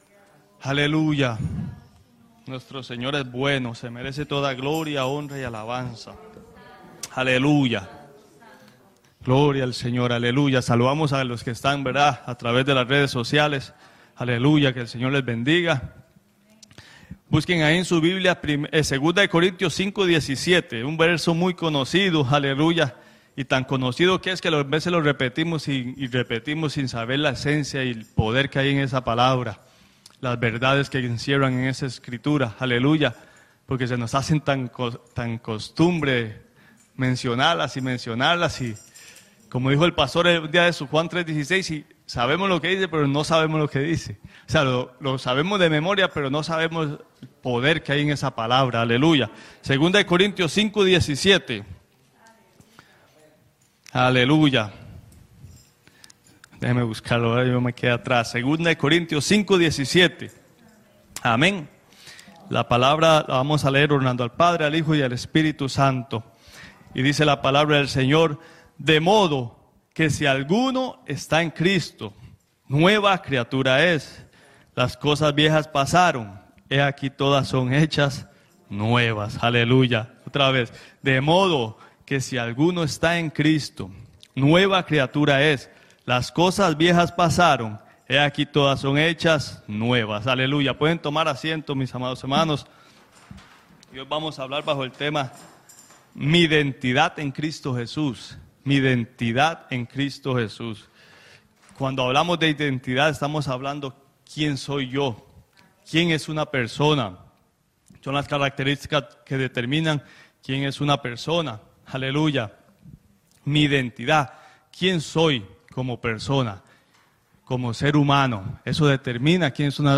Tema: Mi Identidad En Cristo | Predica